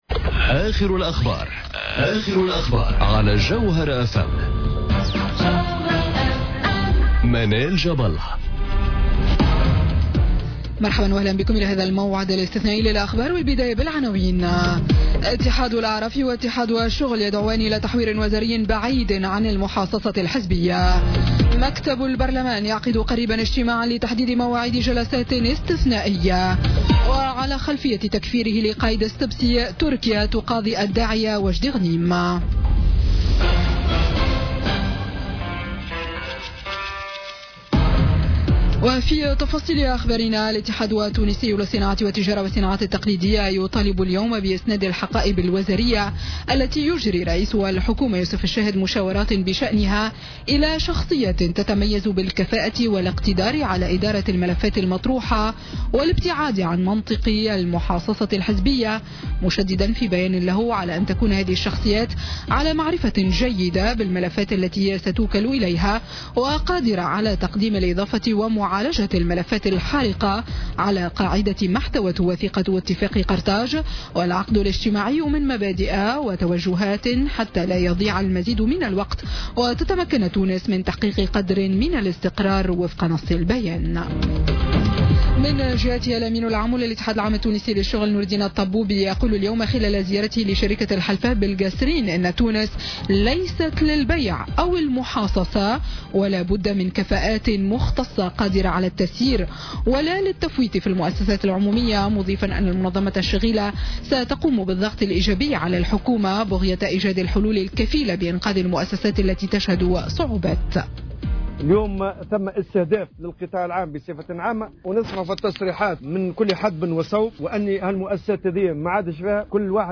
نشرة أخبار السابعة مساء ليوم الجمعة 25 أوت 2017